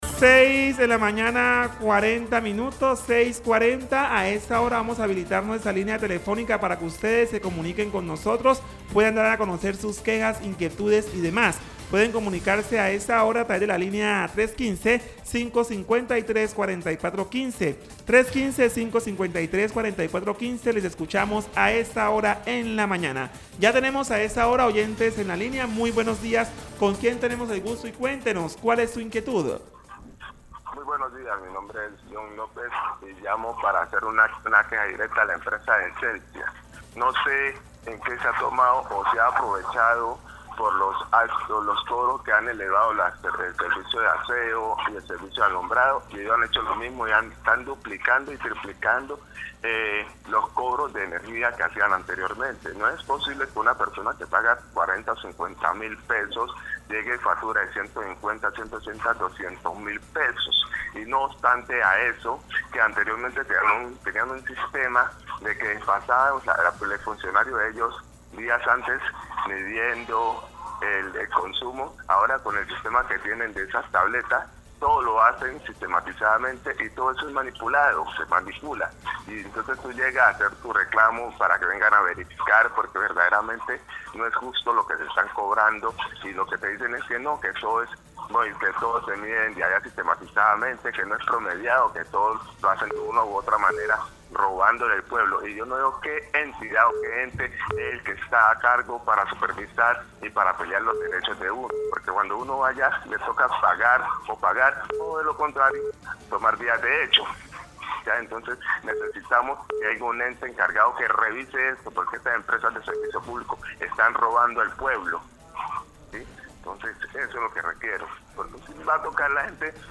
llamadas de oyentes
En la sección de llamadas de los oyentes, varios bonaverenses expresan sus quejas por los altos costos de las facturas de energía de Celsia. La segunda oyente que llama, se queja por las luminarias de alumbrado público apagadas y amenaza con bloqueos sino se arregla el problema de oscuridad.